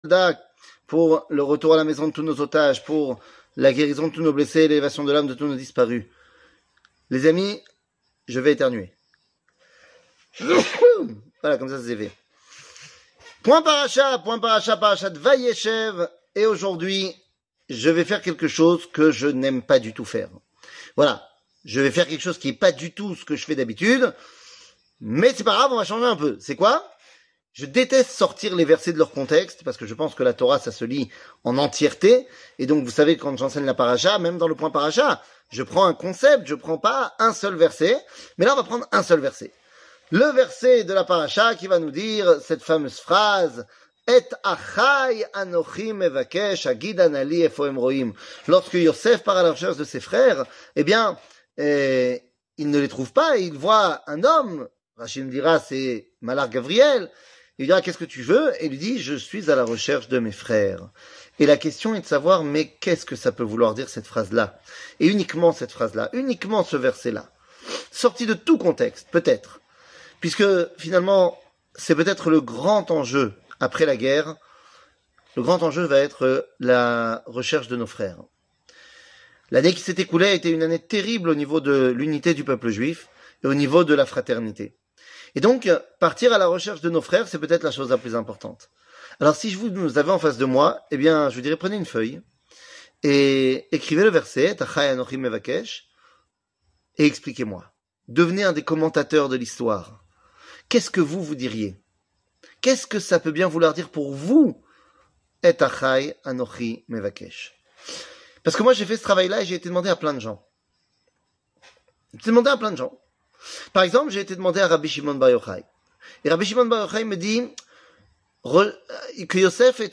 Le point Paracha, Vayechev, Mes freres 00:06:03 Le point Paracha, Vayechev, Mes freres שיעור מ 07 דצמבר 2023 06MIN הורדה בקובץ אודיו MP3 (5.53 Mo) הורדה בקובץ וידאו MP4 (10.02 Mo) TAGS : שיעורים קצרים